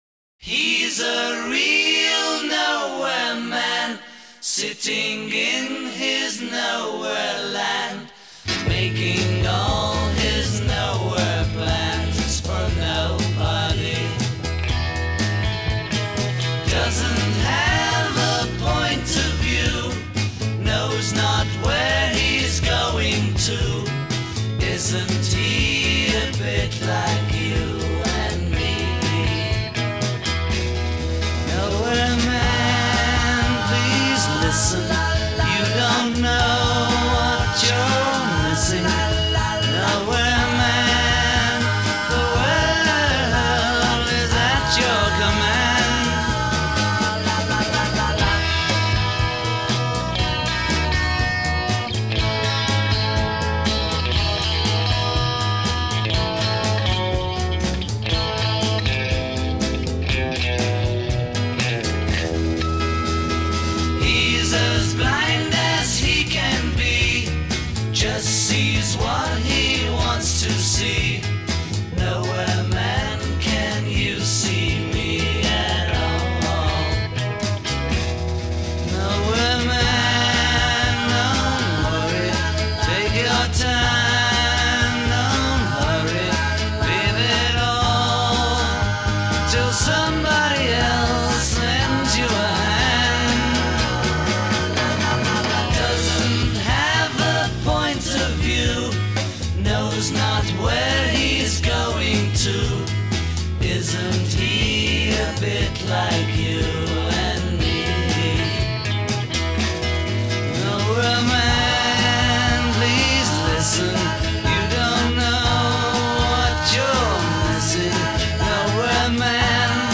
The song sounded sad.